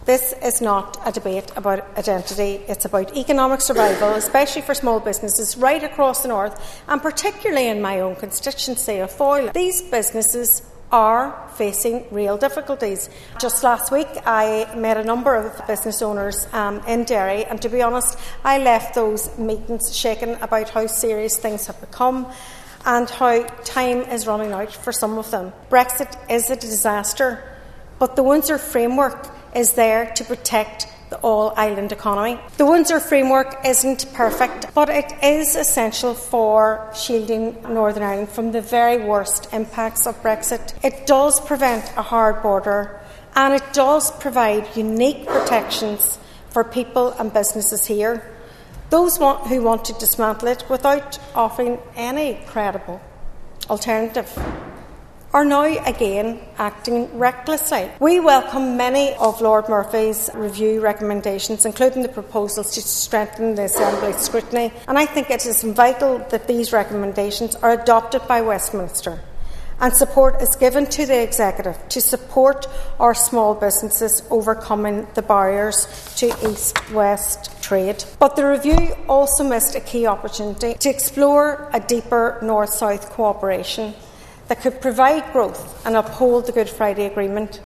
Speaking during  an Assembly debate on the Windsor Framework, Foyle MLA Sinéad McLaughlin said small and medium-sized businesses are fighting to survive because of a disastrous Brexit deal championed by the DUP.